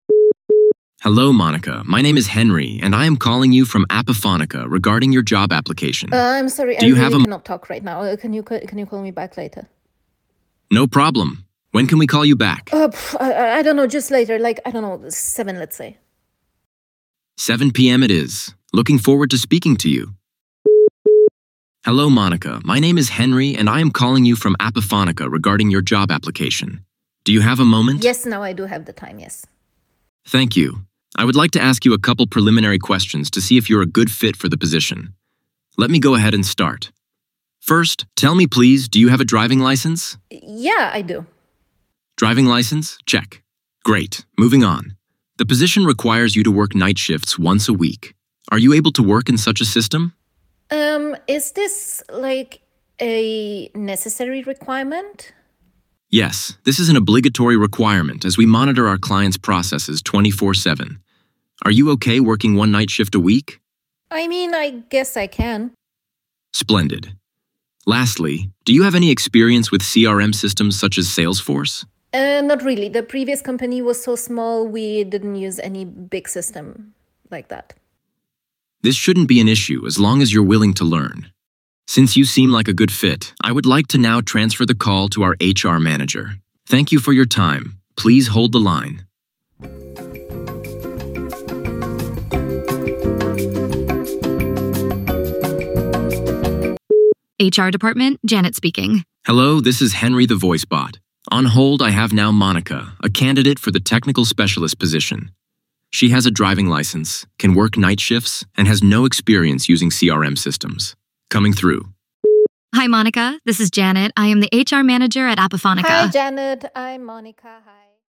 Natural-sounding voice
For example, Apifonica’s voicebots have voices with the right intonation and which are incredibly impressive – there are pauses, tones and even breaths...
Listen to what a modern voicebot sounds like:
A job interview
INTERVIEW 2 EN.mp3